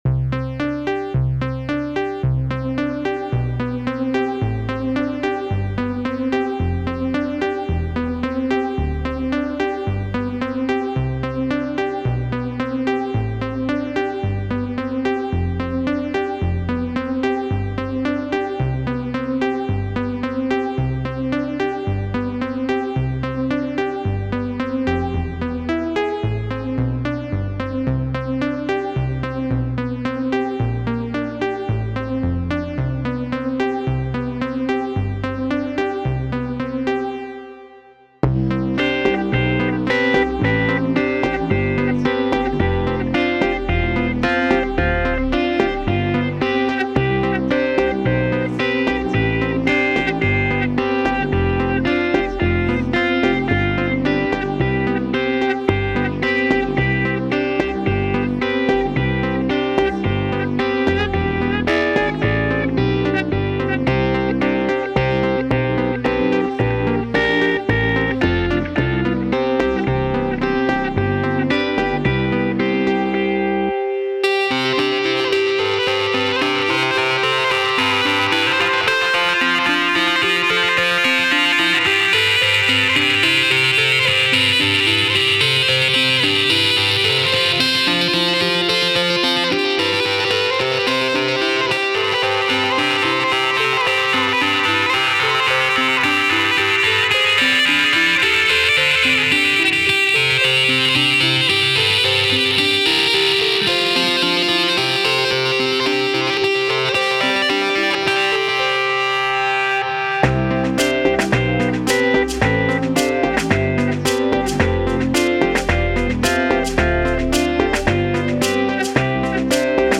AUDIO (INSTRUMENTAL)